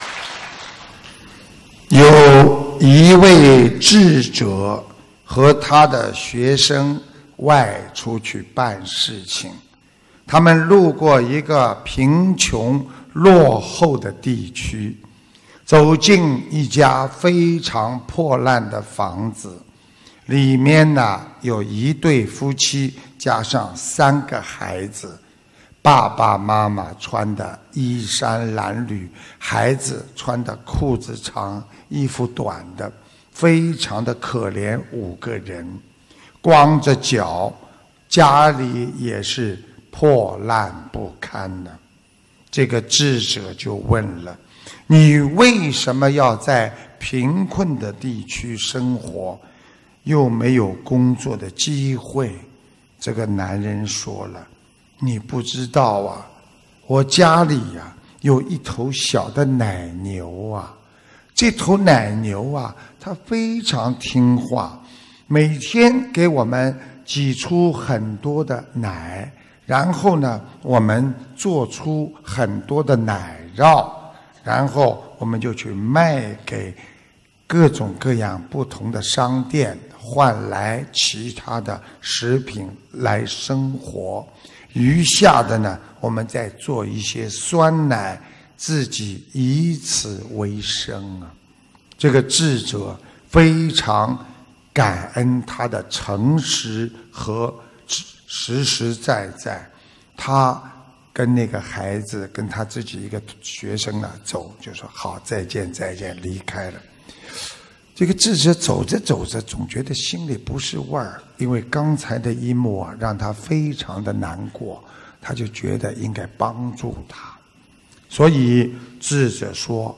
▶ 语 音 朗 读 点击进入 ☞ 首页 > 每日 畅听 平时你们难过、烦恼， 多听听师父 的录音 ， 会笑的。